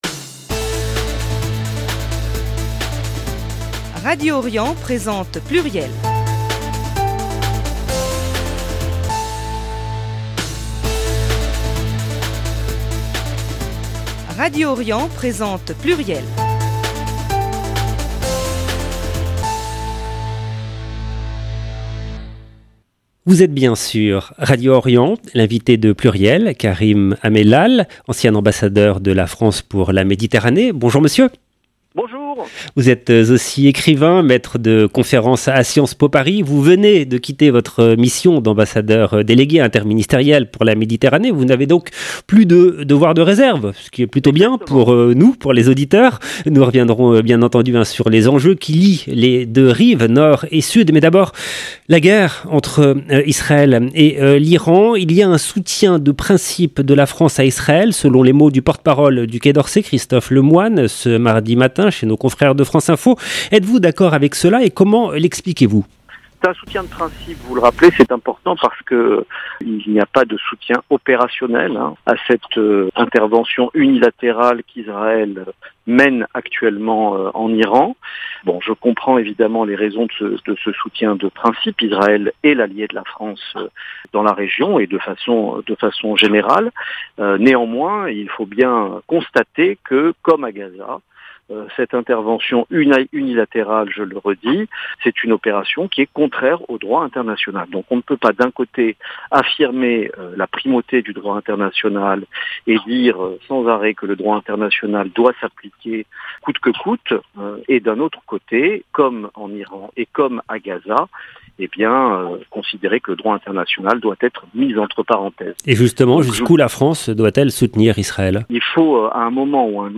L’invité de Pluriel : Karim Amellal, ancien ambassadeur de la France pour la Méditerranée, écrivain, maitre de conférence à Sciences po Paris.